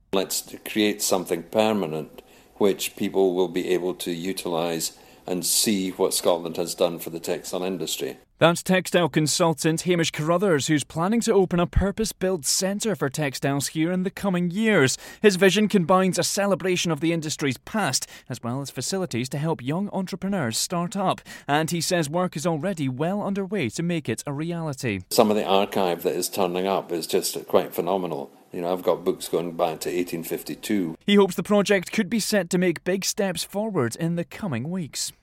In our latest special report on the Scottish Borders textile industry